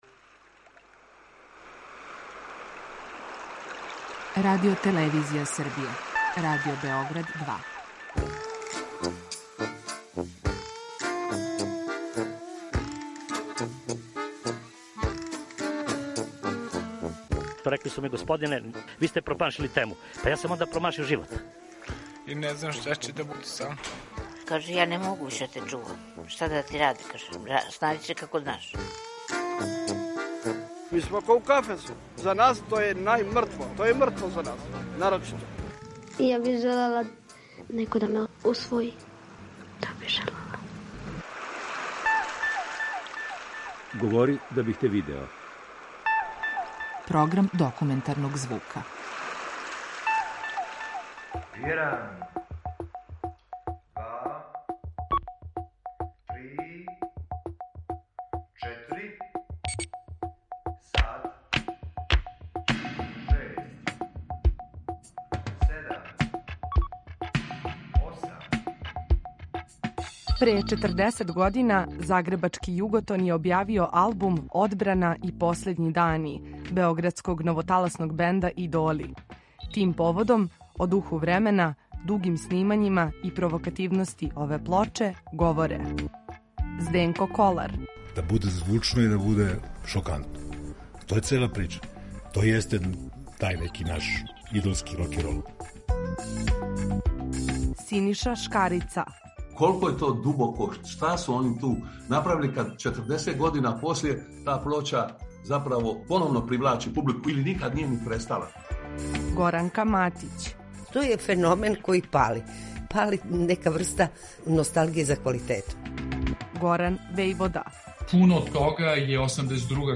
Документарни програм (реприза)